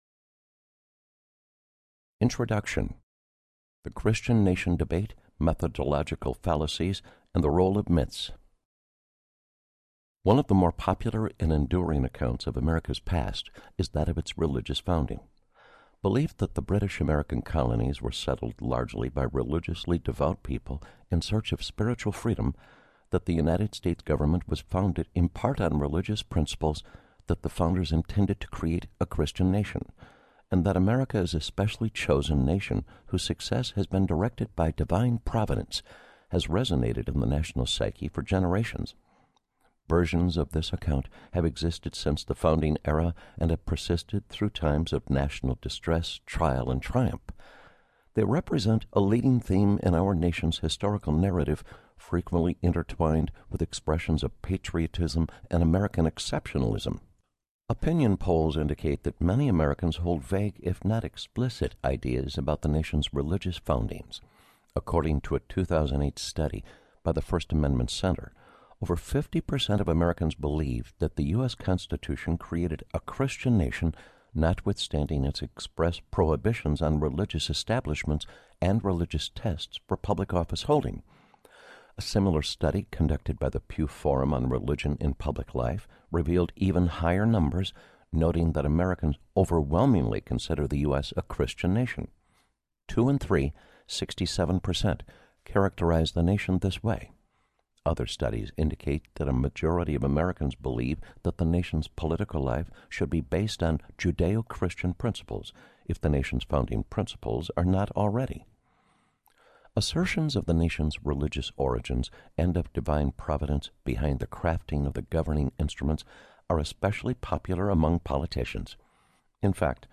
Inventing a Christian America Audiobook
11.0 Hrs. – Unabridged